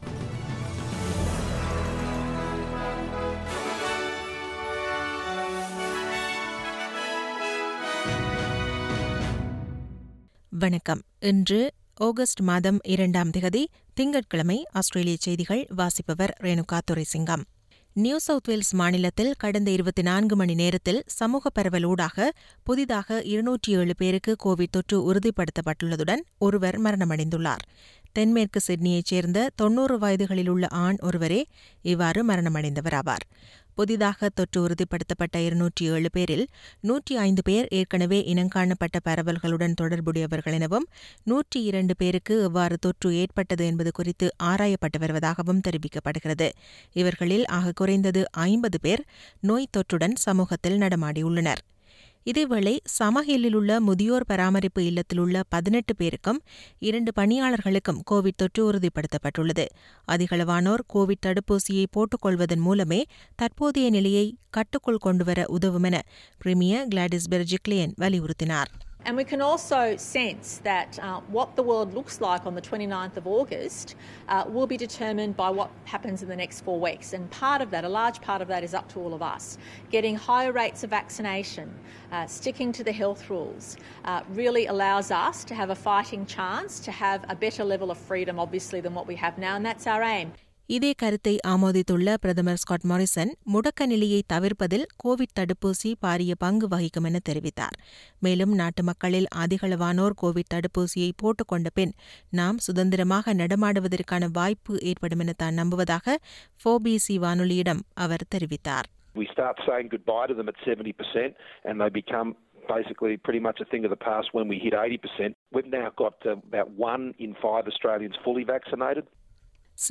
SBS தமிழ் ஒலிபரப்பின் இன்றைய (திங்கட்கிழமை 02/08/2021) ஆஸ்திரேலியா குறித்த செய்திகள்.